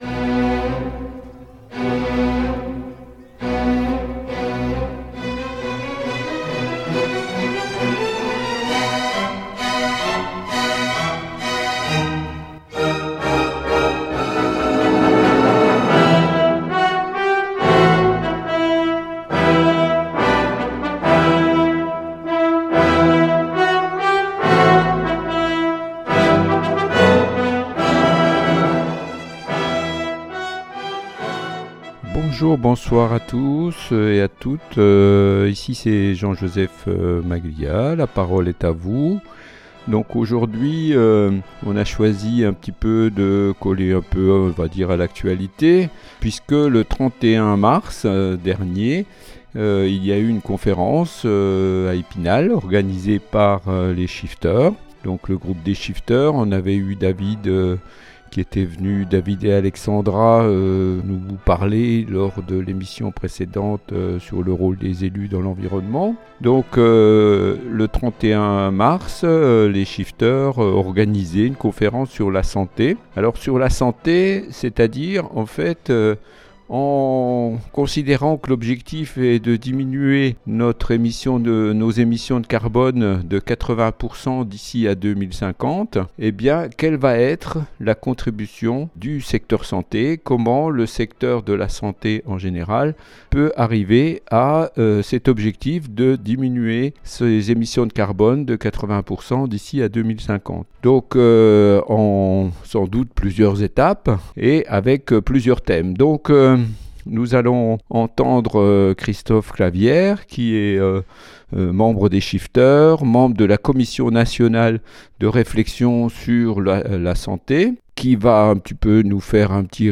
à la conférence consacrée au lien entre climat et santé, organisée le mardi 31 mars à l’amphithéâtre de la faculté de droit d’Épinal par l’association des Shifters